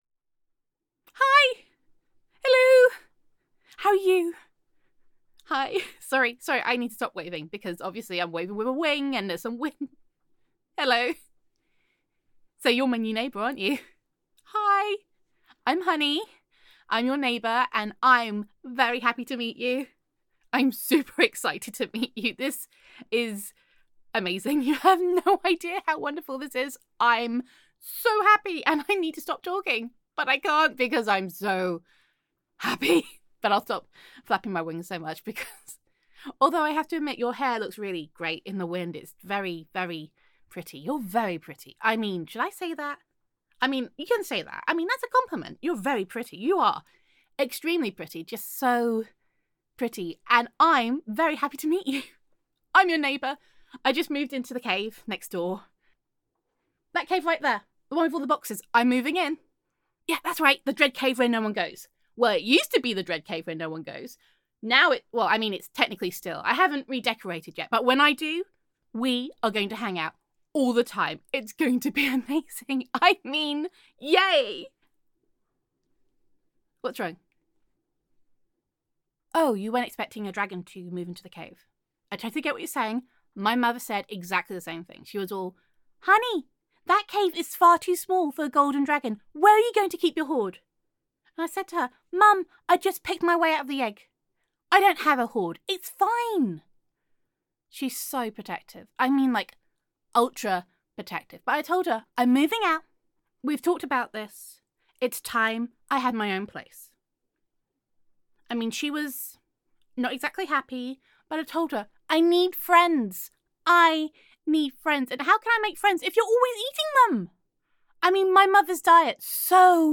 [F4A] Honey the Golden Dragon [Perky][Happy Dragon Sounds][Shiny Shiny][Want to Be My New Best Friend][Let’s Go to IKEA][Making Friends Is Hard When Your Mother Eats Them][Gender Neutral][Meeting Your New Happy Hyper Dragon Neighbour]